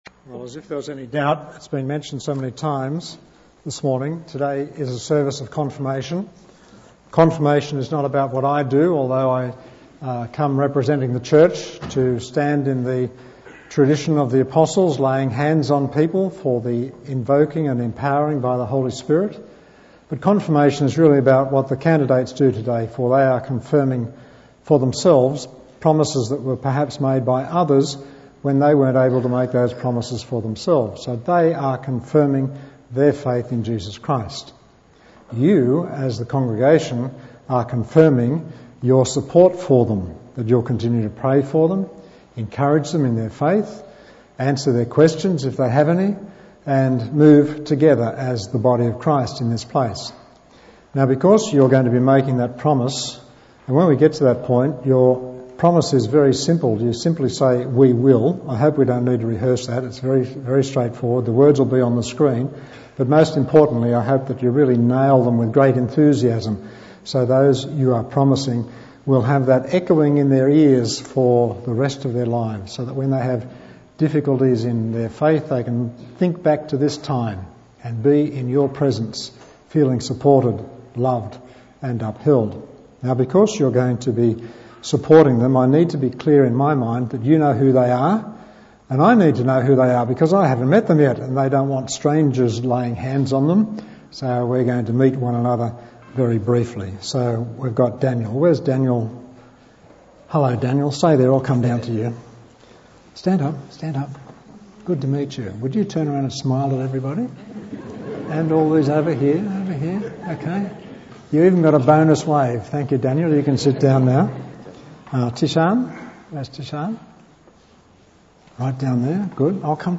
Bible Text: Mark 9:38-50 | Preacher